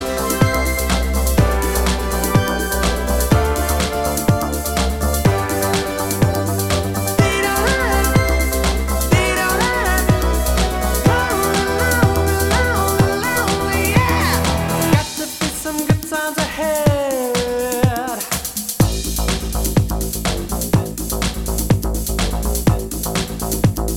Remix Pop (1980s) 3:35 Buy £1.50